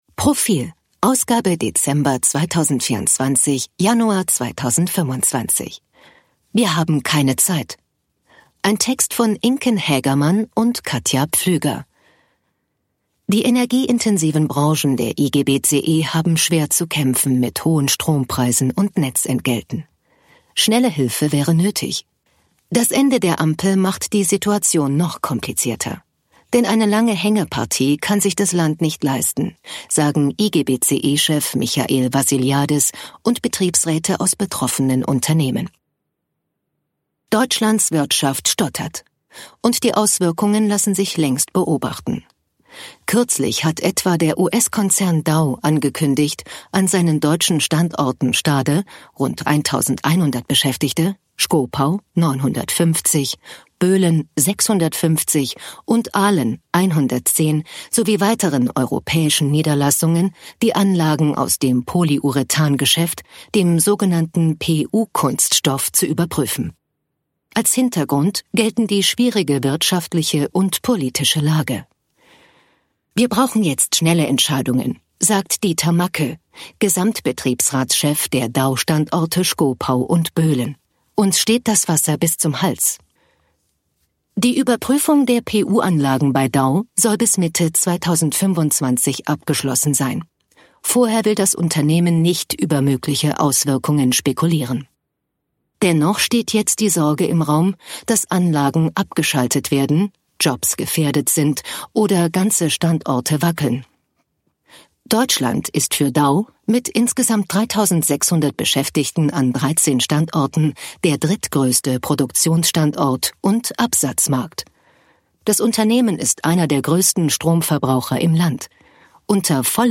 Artikel vorlesen lassen ▶ Audio abspielen
246_Reportage_64.mp3